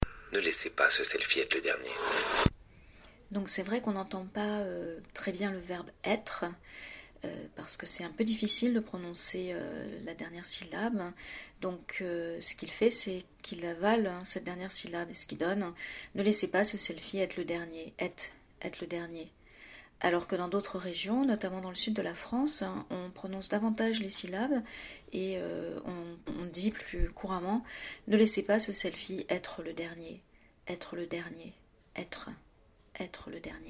Peut-être se débrouillerait-il mieux avec un accent du sud, dans lequel toutes les syllabes sont prononcées plus distinctement.
Ecoutez la petite différence:
selfie-prononciation-2.mp3